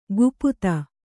♪ guputa